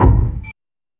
Creak
mysterious.mp3